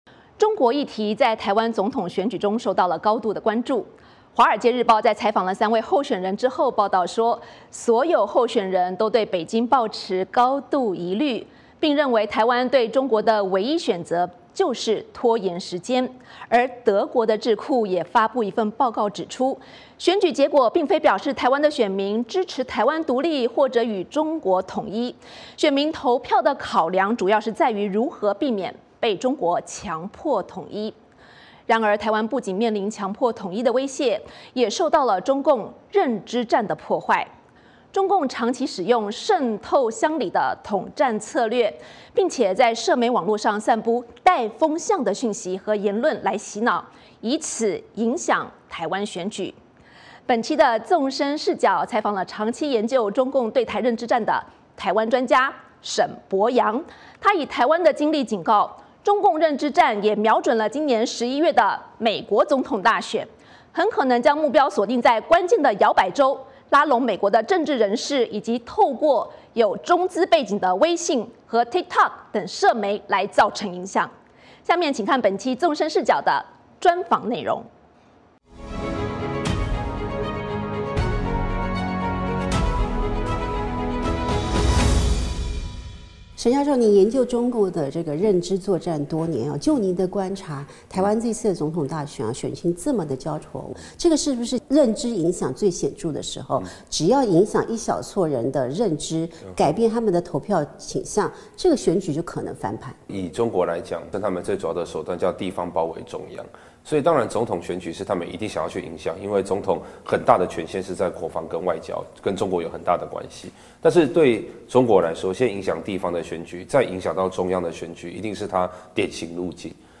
专访沈伯洋：如何辨识中共认知战手法